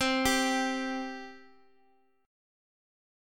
C5 chord
C-5th-C-x,x,x,x,1,3-8-down-Guitar-Standard-1.m4a